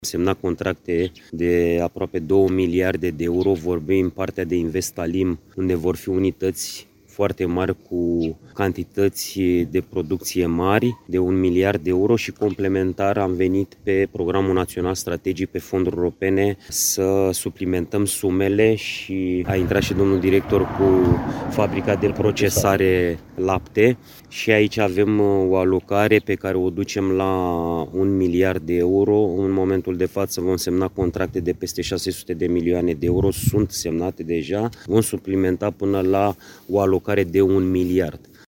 Prezent astăzi la Peşteana, în judeţul Hunedoara, ministrul Agriculturii, Florin Barbu, a precizat că programul are o finanţare totală de aproape 2 miliarde de euro.